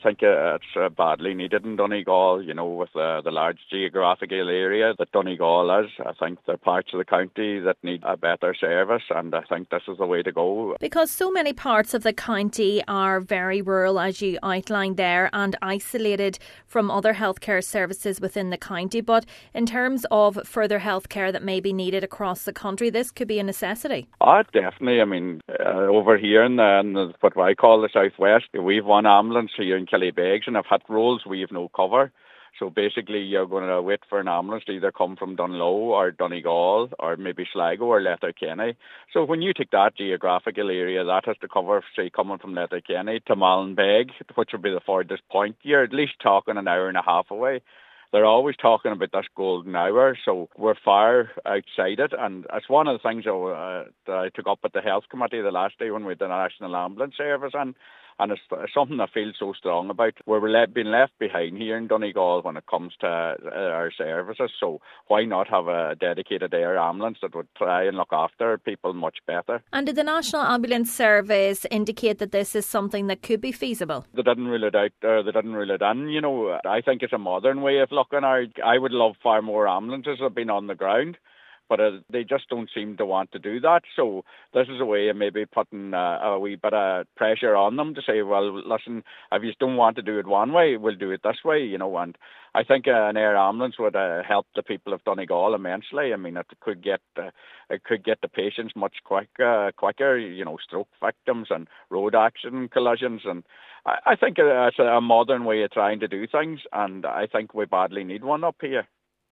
Senator Boyle says he has raised the need for an air ambulance in Donegal with the National Ambulance Service: